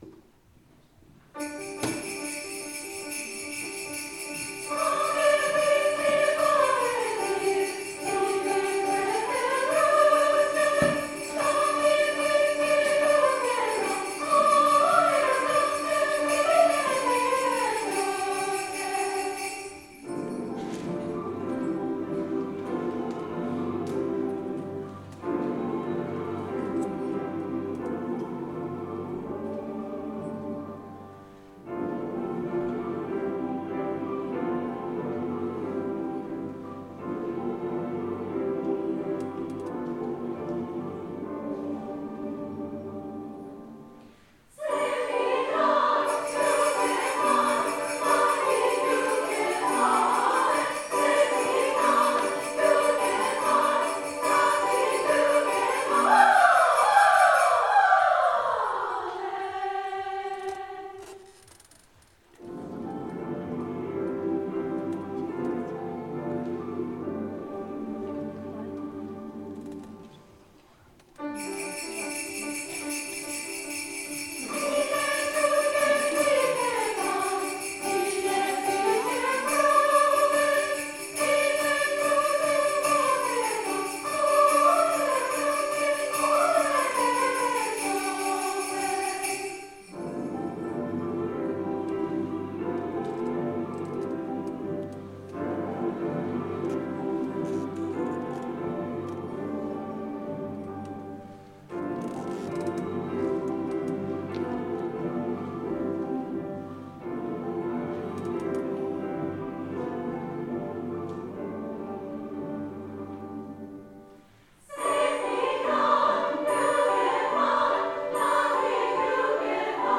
our May 4th concert
Chramer, gip die varwe mir     Soprano and Coro